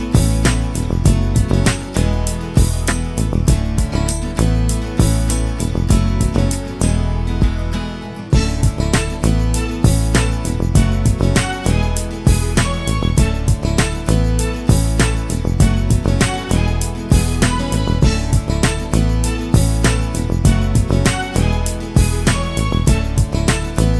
For Solo Female Pop (1990s) 3:36 Buy £1.50